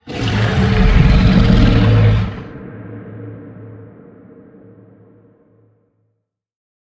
Sfx_creature_bruteshark_callout_04.ogg